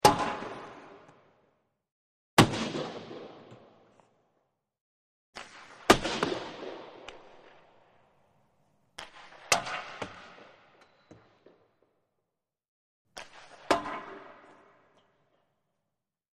Hockey: Puck Shot To Board ( 5x ); Hockey Stick Hits Glass; Five Times; Clap, Rattle / Squeak Of Glass, Echo, One Time; Multiple Clap, Rattle / Squeak Of Glass, Echo, Close Perspective.